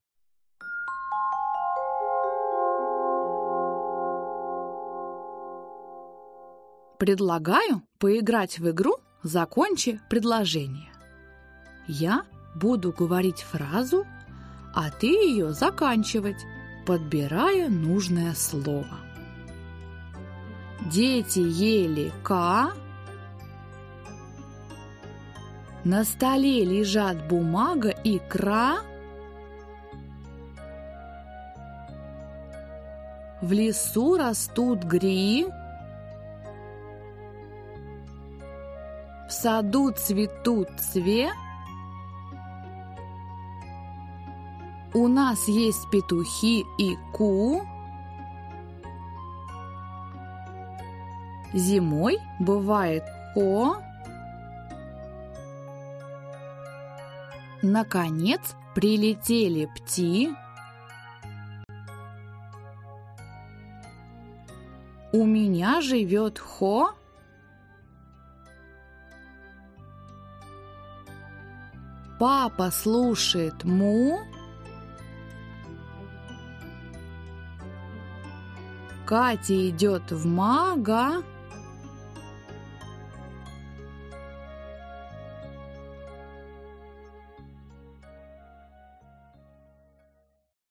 Аудиокнига Говорящий логопед | Библиотека аудиокниг
Прослушать и бесплатно скачать фрагмент аудиокниги